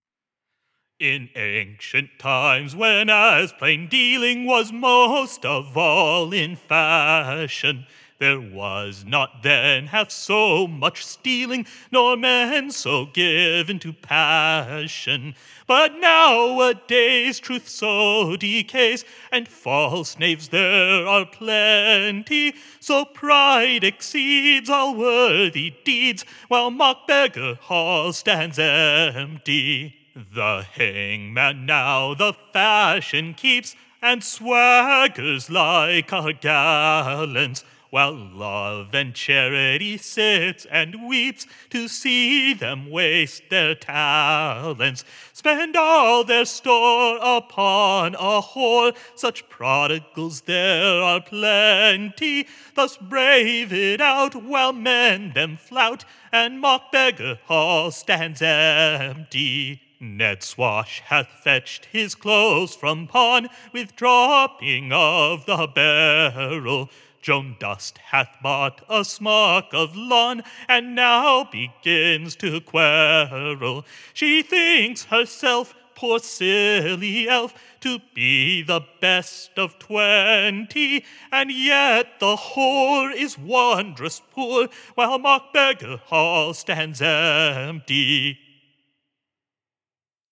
Second “Mock-Beggar Hall” ballad
” bitingly